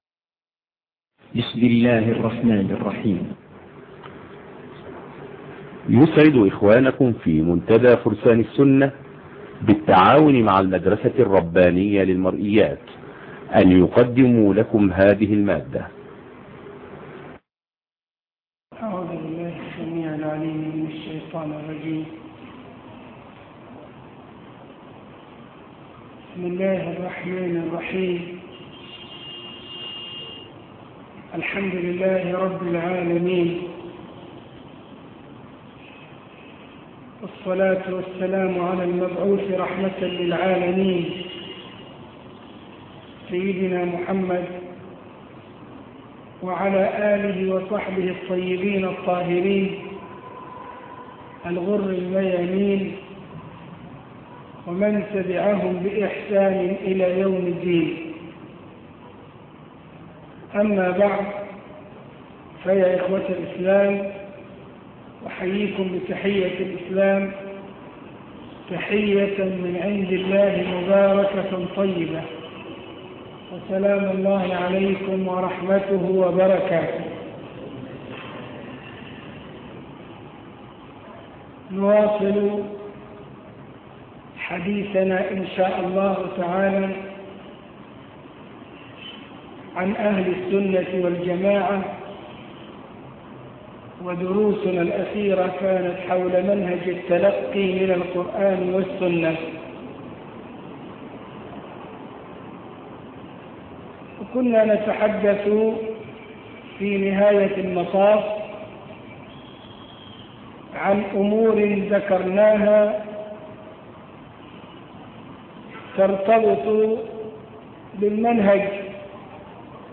عنوان المادة الدرس ( 17) شرح درة البيان فى أصول الإيمان تاريخ التحميل الأثنين 1 مارس 2021 مـ حجم المادة 37.17 ميجا بايت عدد الزيارات 365 زيارة عدد مرات الحفظ 163 مرة إستماع المادة حفظ المادة اضف تعليقك أرسل لصديق